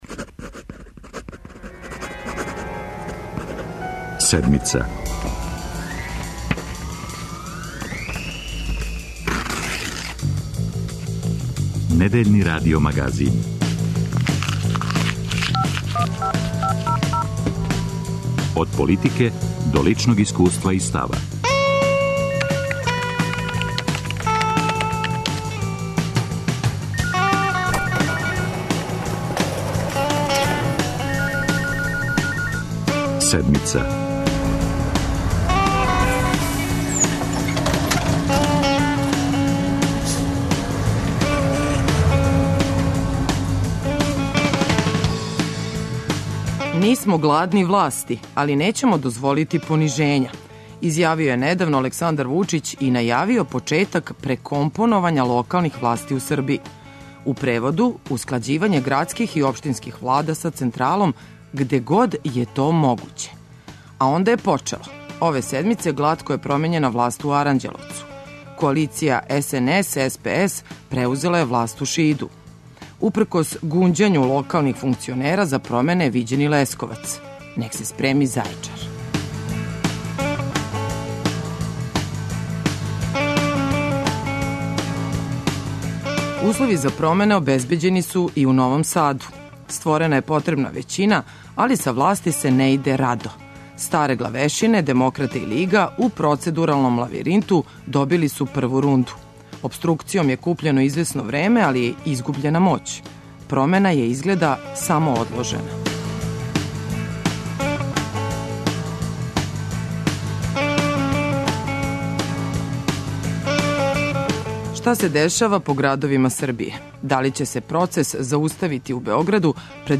Седмица ове недеље о узроцима и сврси нових савеза на локалу. Гости емисије су страначки и месни функционери у Новом Саду, Зајечару, Лесковцу и Книћу.